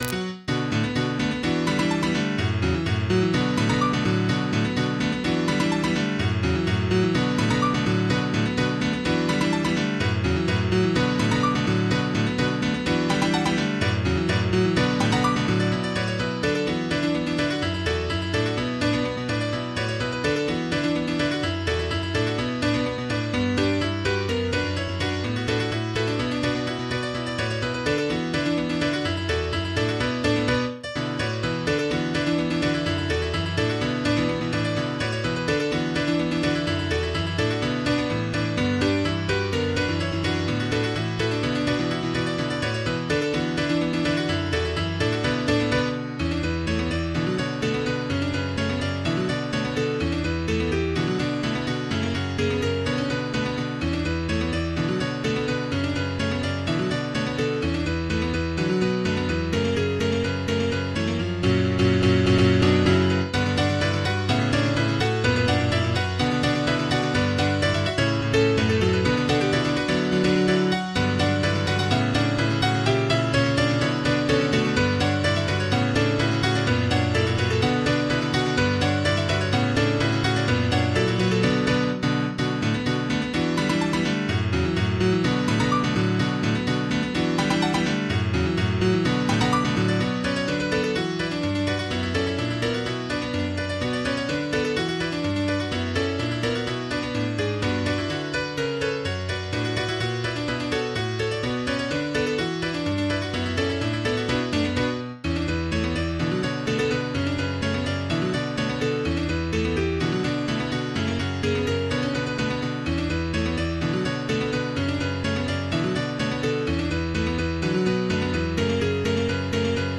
MIDI 31.71 KB MP3 (Converted) 3.13 MB MIDI-XML Sheet Music